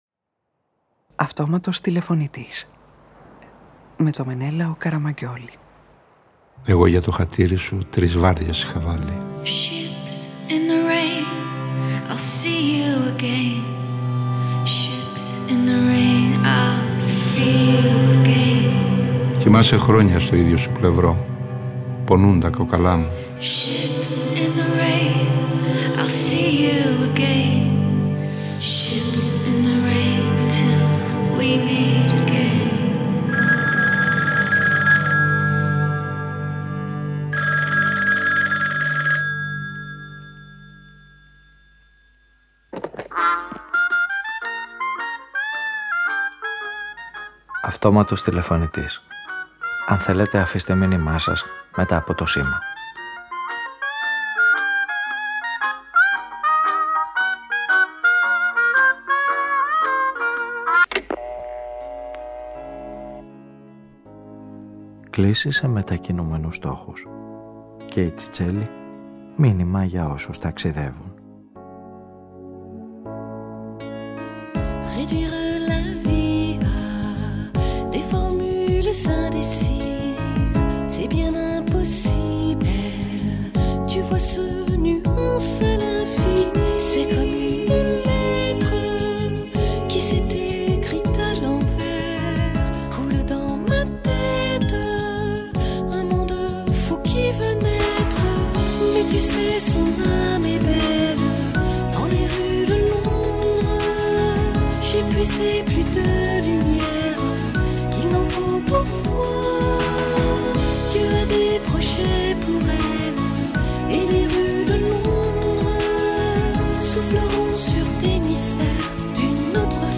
Ραδιοφωνικη Ταινια